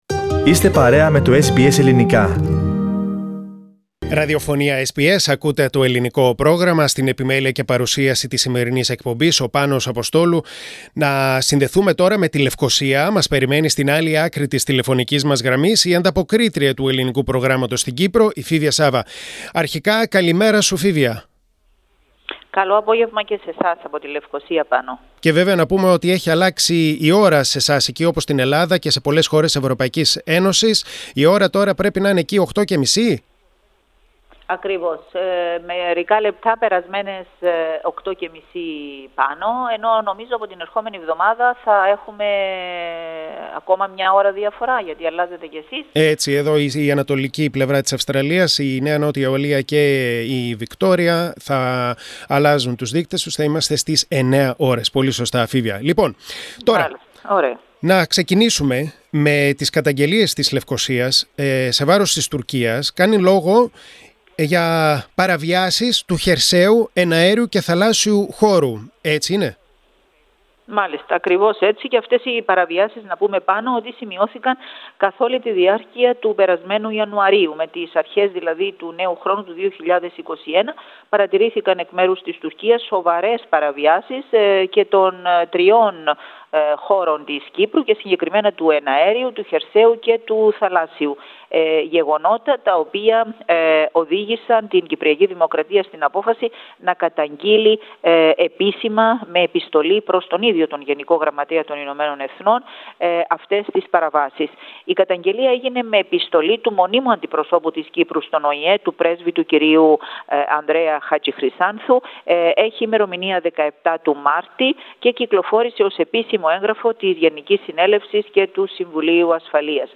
Τη σημασία της κατοχύρωσης προϊόντος Προστατευόμενης Ονομασίας Προέλευσης (Π.Ο.Π) για το κυπριακό χαλούμι, επισήμανε ο πρόεδρος της Κυπριακής Δημοκρατίας, Νίκος Αναστασιάδης. Ακούστε ολόκληρη την ανταπόκριση